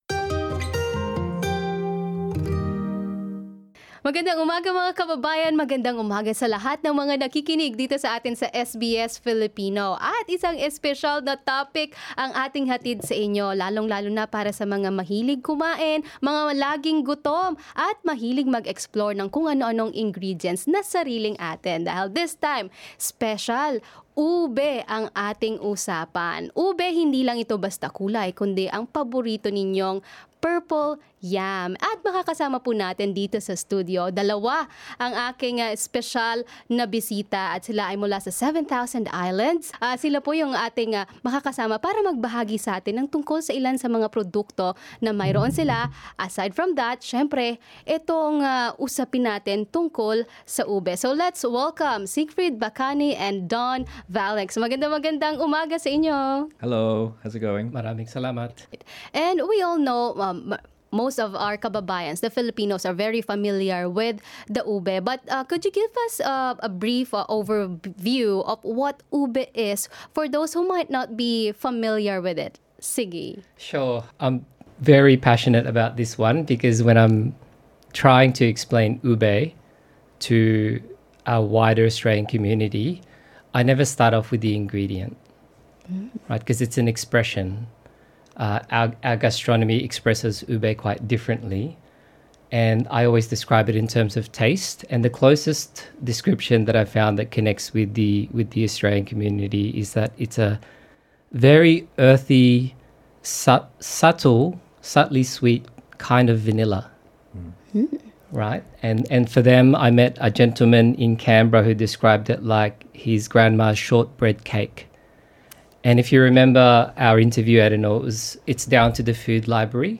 ube-full-interview.mp3